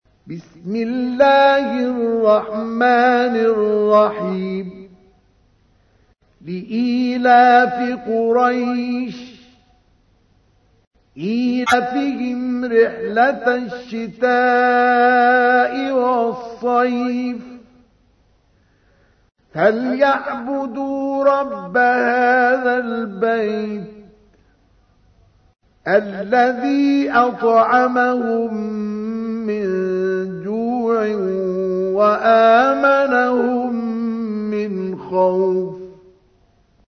تحميل : 106. سورة قريش / القارئ مصطفى اسماعيل / القرآن الكريم / موقع يا حسين